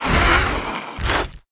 Index of /App/sound/monster/skeleton_wizard